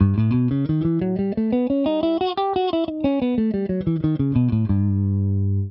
Tonique M2 M3 #4 #5 M6 M7 Tonique
Exemple audio " Sol Lydien augmenté "
Gamme guitare Jerrock
3-Sol_Lydien_augmente.wav